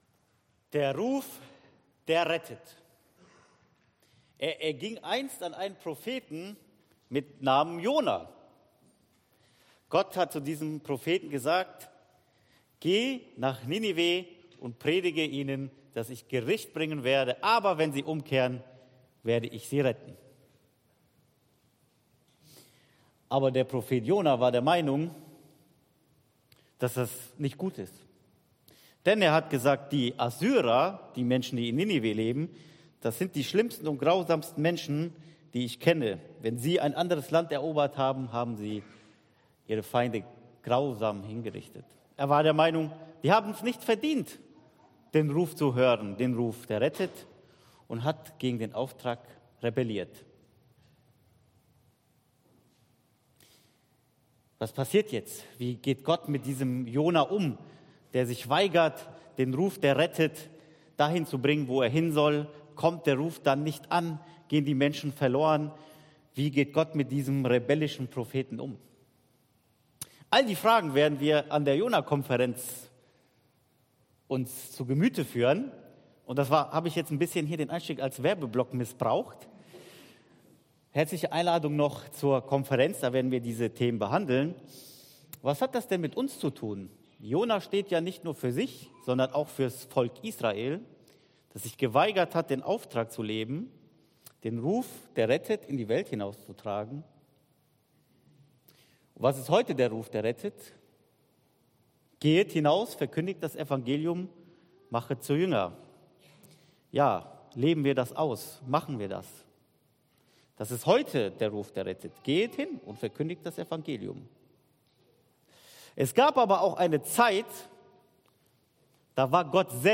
Re:deemed (Erlöst): Gnade, die alles erneuert ~ EFG-Haiger Predigt-Podcast Podcast